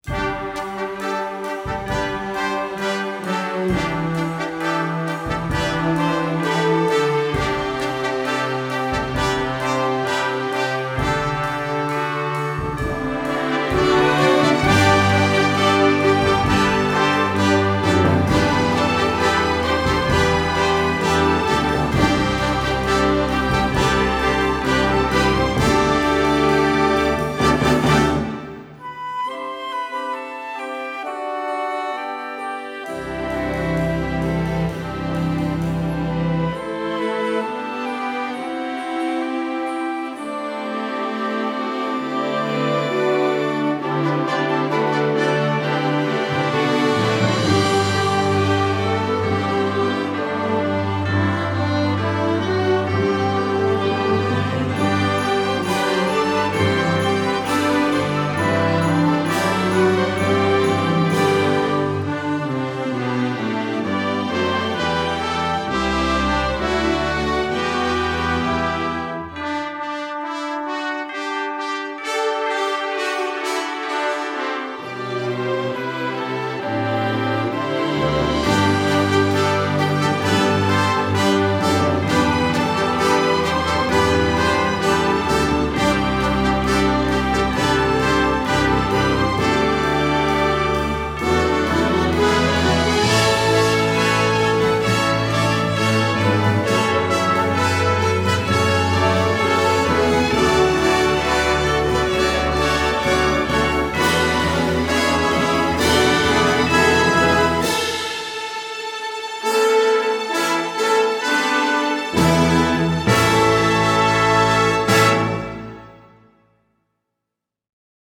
Instrumental Orchestra Full Orchestra
Full Orchestra